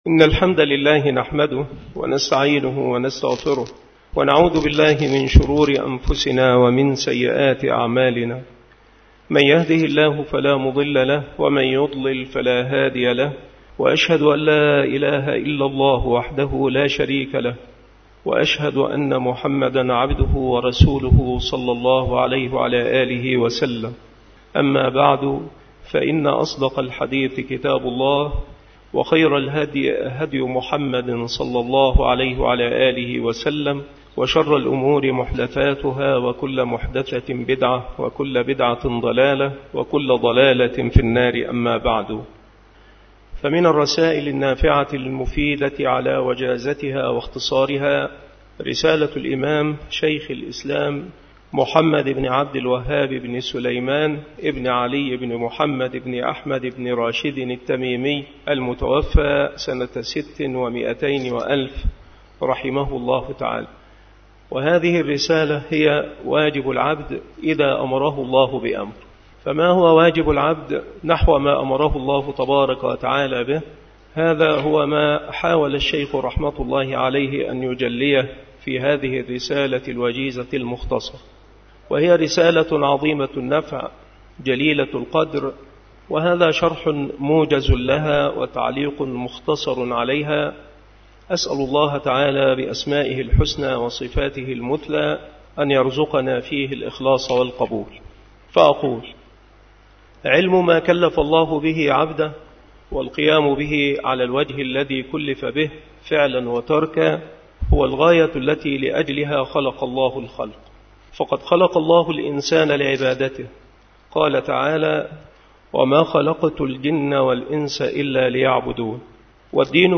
مكان إلقاء هذه المحاضرة بمسجد الغفران بالحي الثامن بمدينة نصر - القاهرة